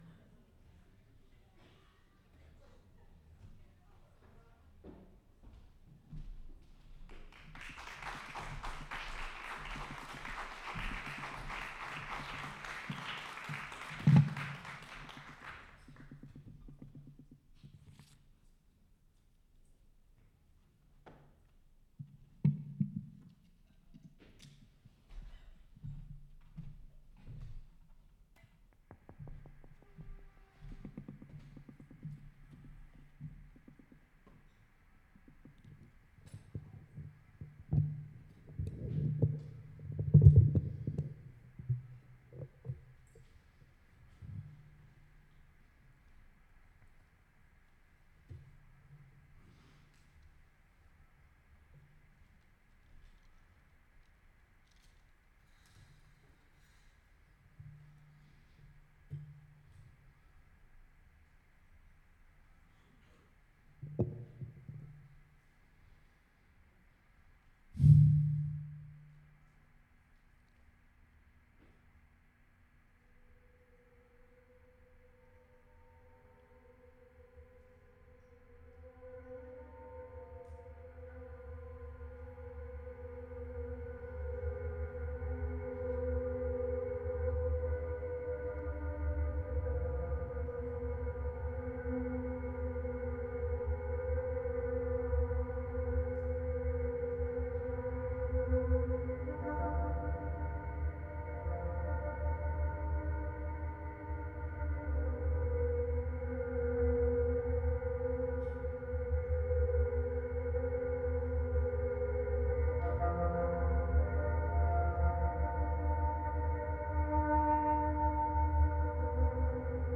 experimental
guitar
vocals